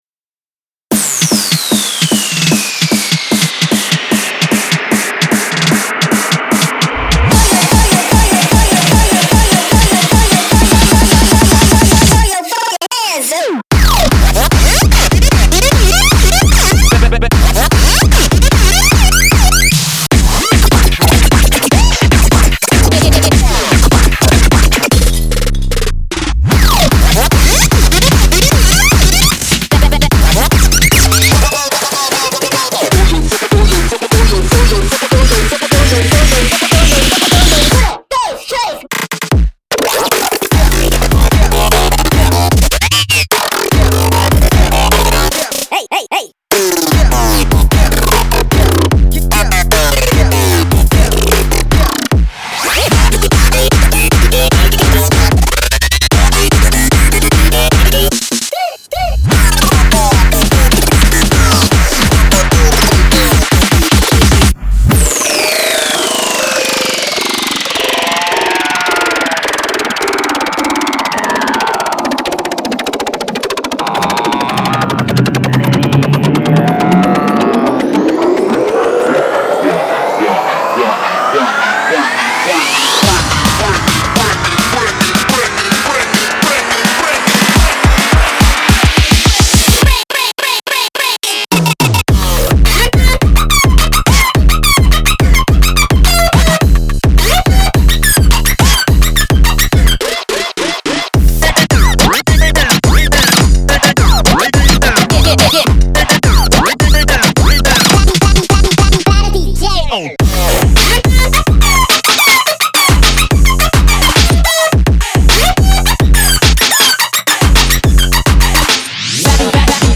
BPM106-150
Audio QualityPerfect (High Quality)
Genre: FESTIVAL SOUND.